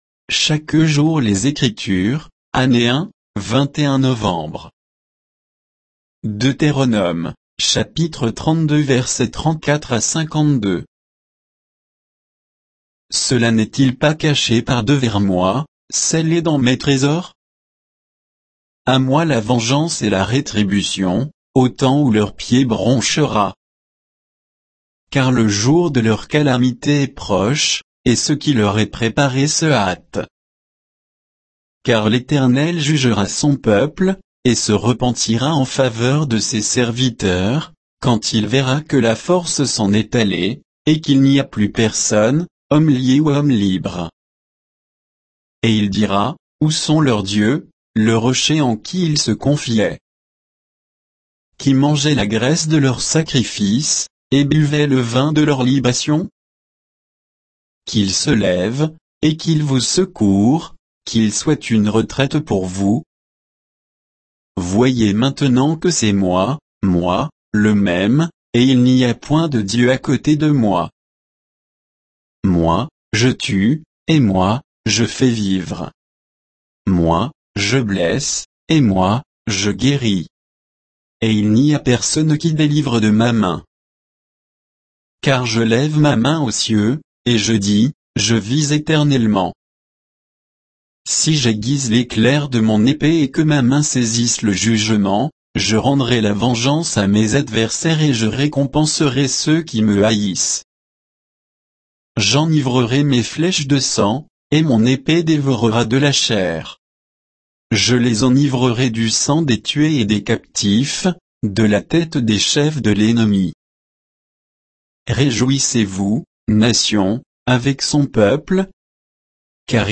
Méditation quoditienne de Chaque jour les Écritures sur Deutéronome 32